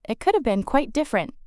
「could’ve」は could haveの短縮系で「クッドゥブ」と発音されます。